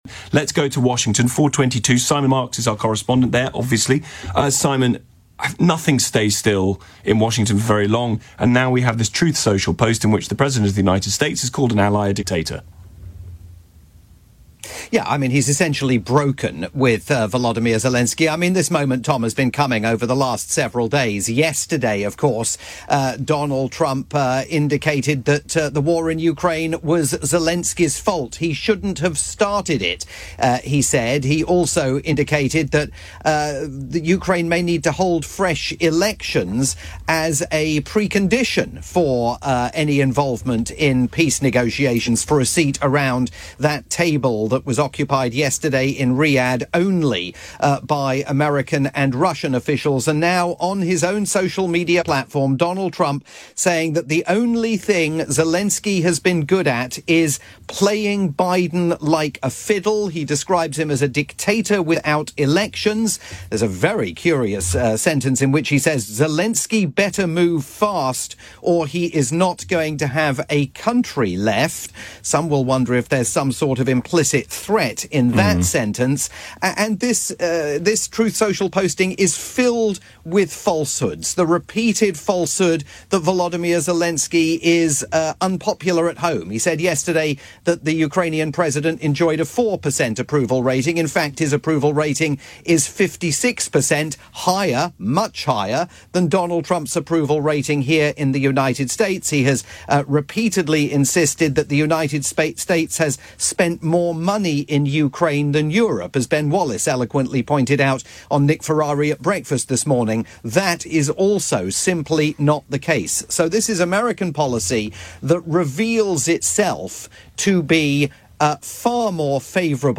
breaking news coverage for the UK's LBC's drivetime programme